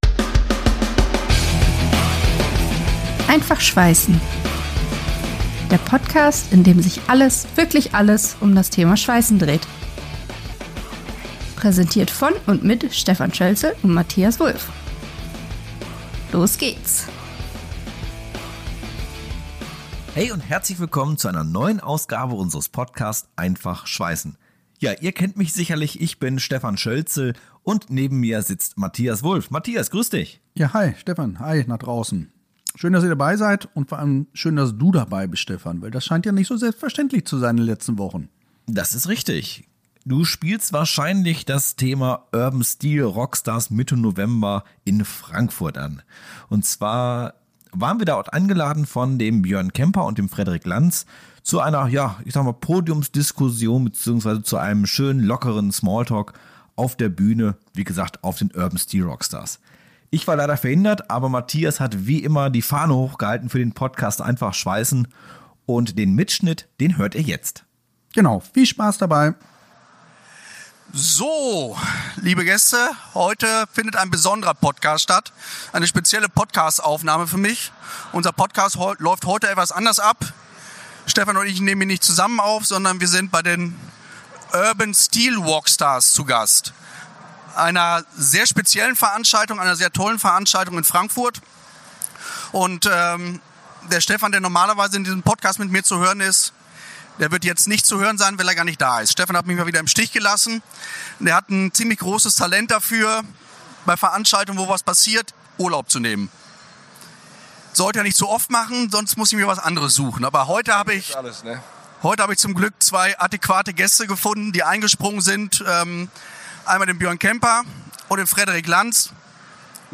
Live-Mitschnitt vom Urban Steel Rockstars Festival aus Frankfurt (November 2025).